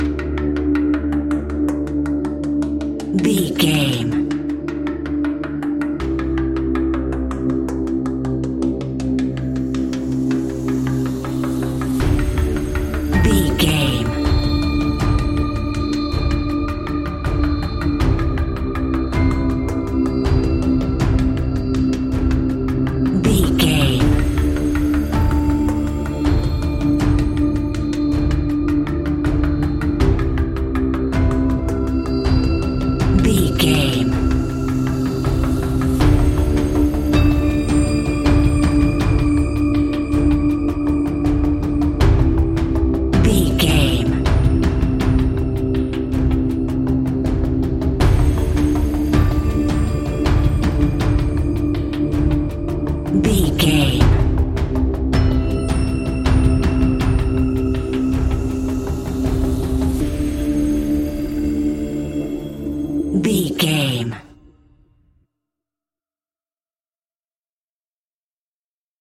In-crescendo
Aeolian/Minor
ominous
dark
haunting
eerie
ticking
electronic music
Horror Pads
horror piano
Horror Synths